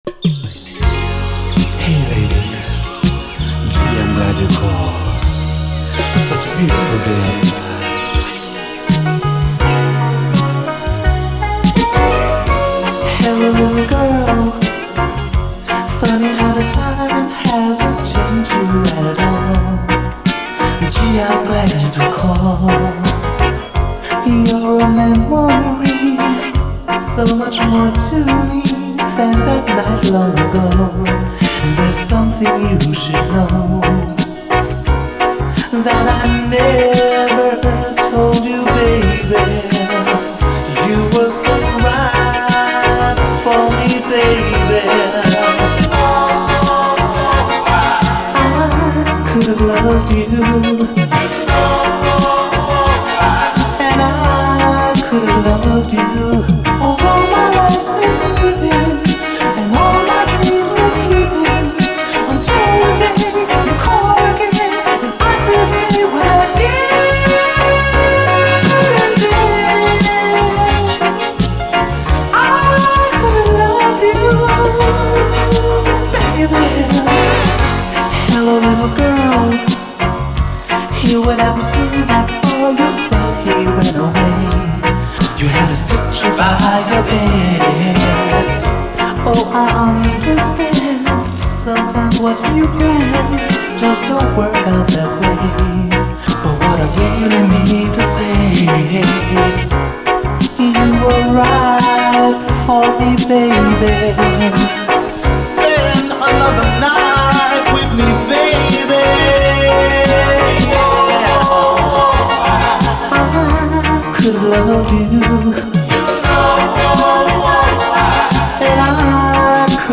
バレアリック＆ダビーに広がるシンセ・サウンド＆コーラス・ハーモニーの美しさがヤバい領域に到達しています。